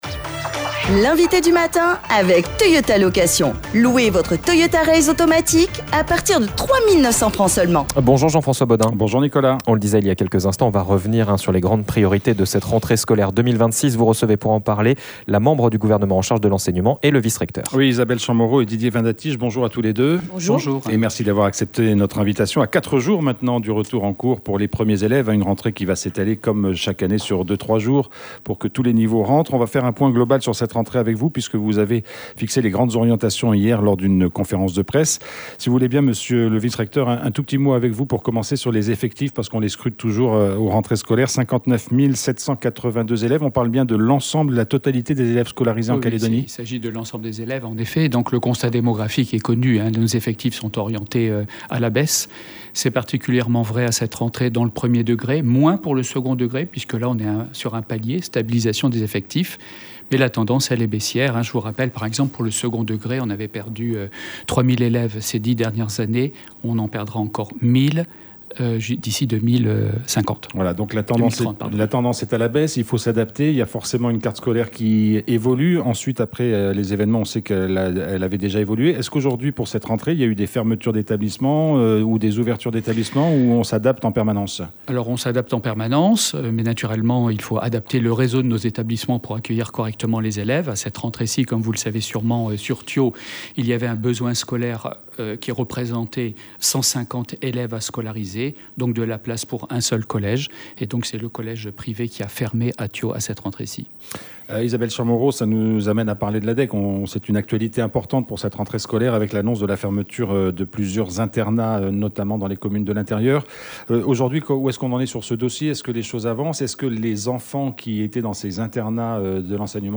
Le vice-rectorat prévoit des modifications du diplôme national du brevet : la part du contrôle continu dans la note finale passe de 50% à 40%, soit une priorité accordée aux examens finaux. Nous en avons parlé ce matin avec Isabelle Champmoreau, membre du gouvernement en charge de l’enseignement, et Didier Vin Datiche, vice-recteur de la Nouvelle-Calédonie.